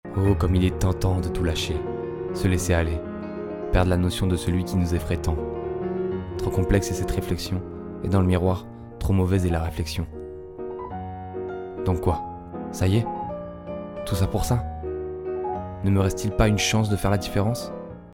Voix off 3
21 - 38 ans - Baryton